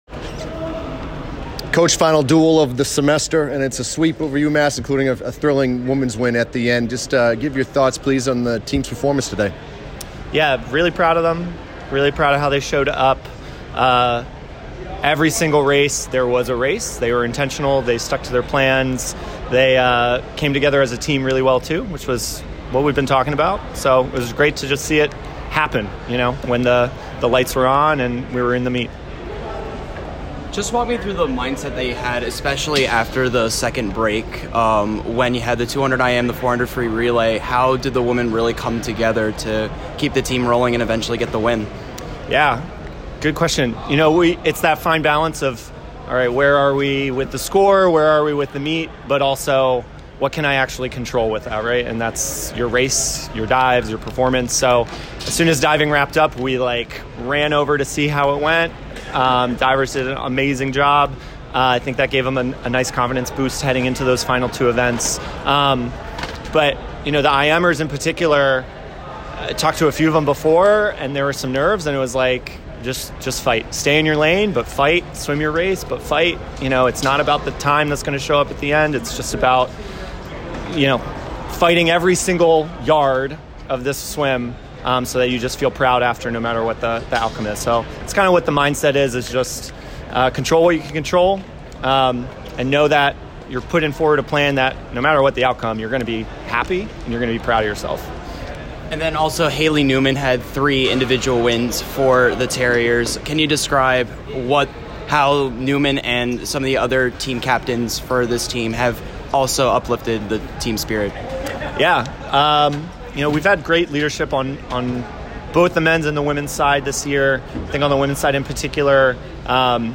UMass Postmeet Interview